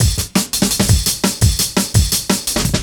cw_170_SliceFunk1.wav